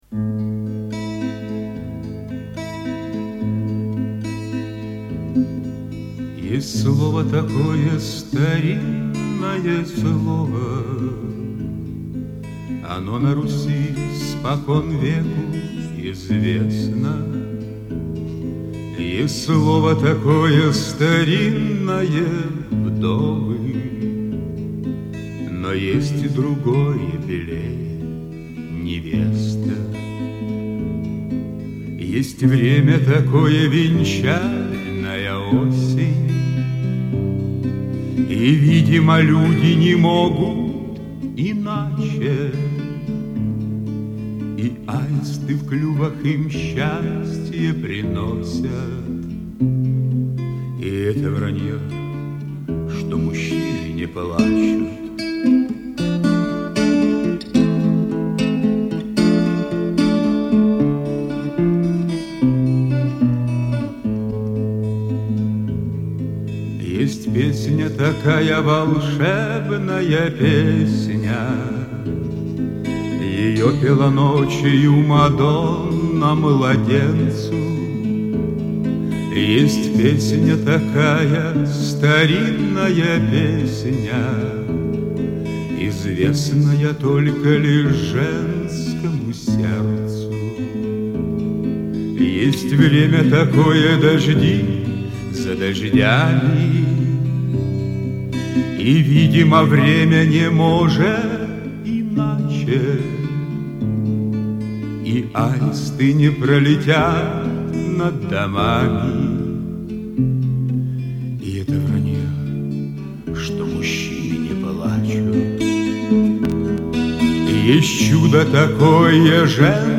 Шансон
Очень симпатичный вокал!